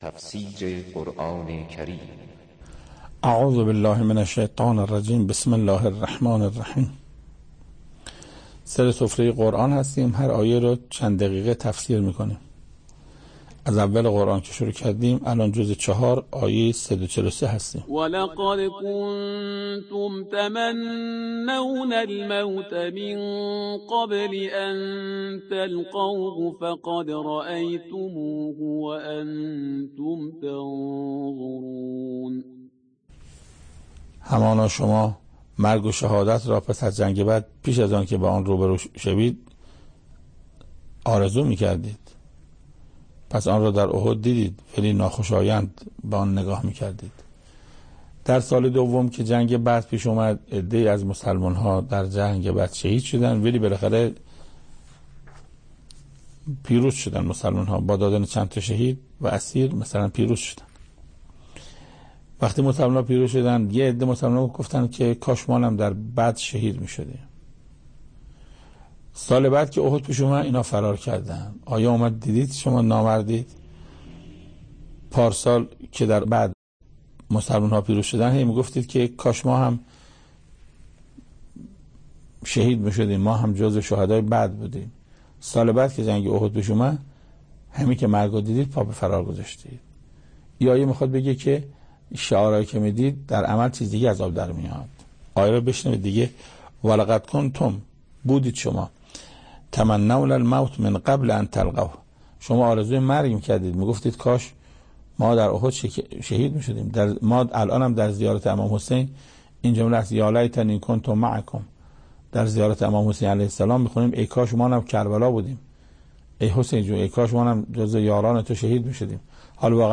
تفسیر آیه 143 و 144 سوره آل عمران - استاد محسن قرائتی | ضیاءالصالحین